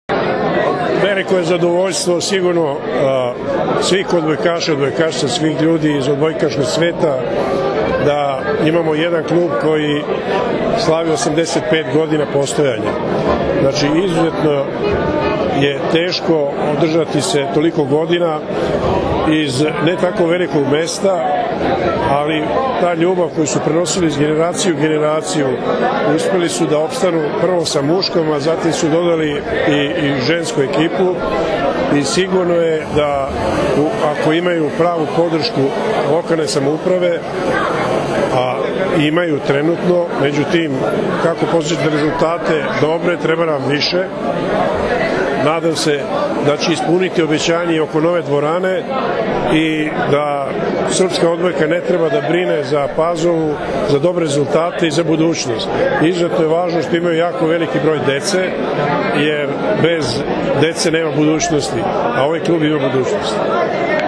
Odbojkaški klub “Jedinstvo” iz Stare Pazove proslavio je sinoć 85 godina postojanja na svečanosti održanoj u hotelu “Vojvodina” u Staroj Pazovi.
IZJAVA